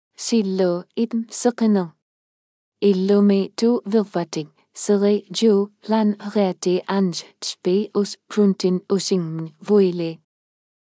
FemaleInuktitut (Syllabics, Canada)
Siqiniq is a female AI voice for Inuktitut (Syllabics, Canada).
Voice sample
Listen to Siqiniq's female Inuktitut voice.